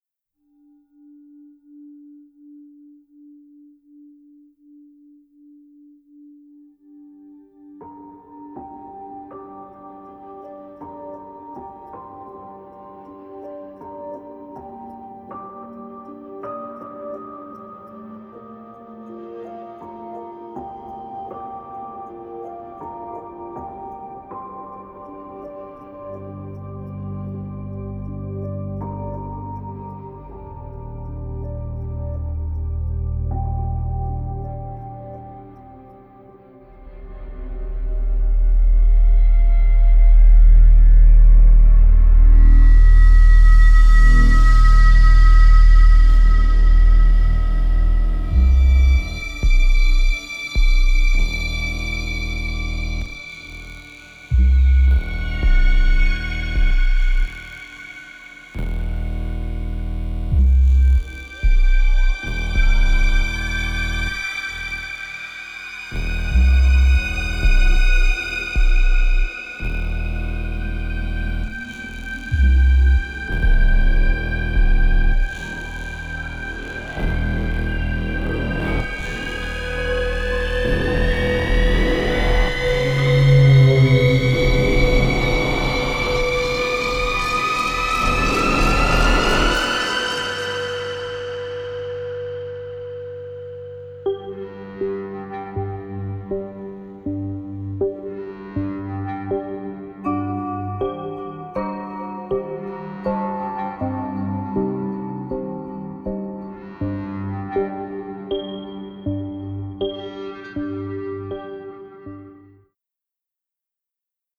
Frightening highlight